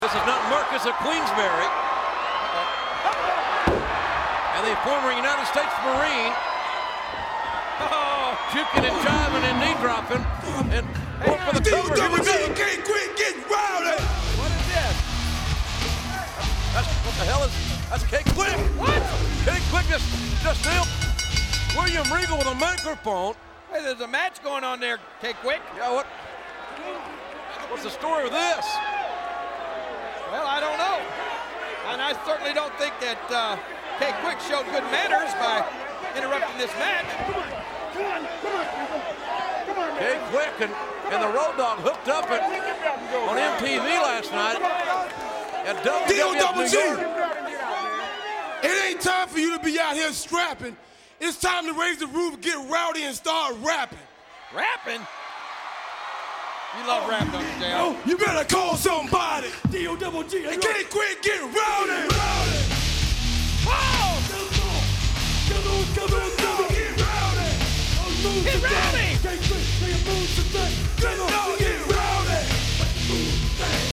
Facing William Regal, Roadie had just hit European champ with his patented knee drop when, apropos of nothing, K-Kwik came on the mic.
shouted the rookie, rushing down to the ring to make the save.